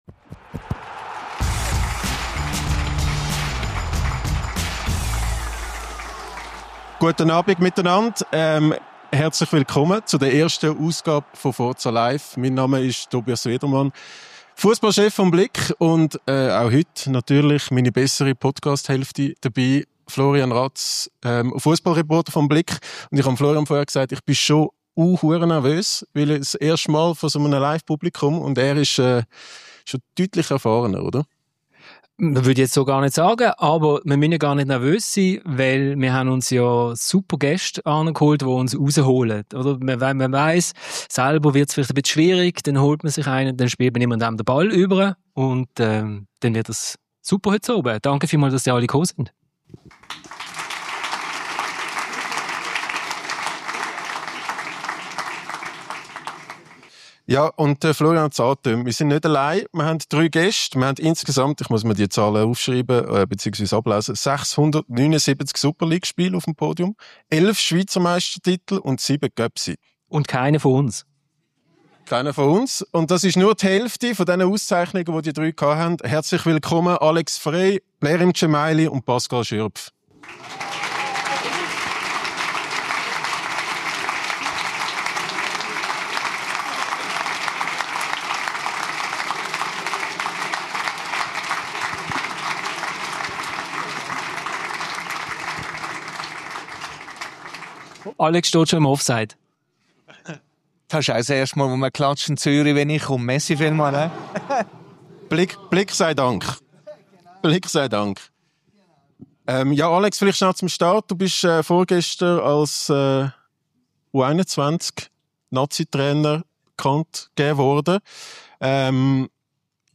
FORZA! #71 – LIVE mit Frei, Dzemaili & Schürpf ~ FORZA!
In der ersten Ausgabe von FORZA! Live im ausverkauften Zürcher Papiersaal